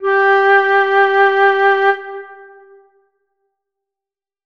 NOTA SOL: